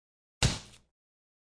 冲头 " 冲头5
描述：吸盘冲到肠道，在工作室录制反应声
Tag: 扑灭 攻击 冲床 冲床 吸盘 命中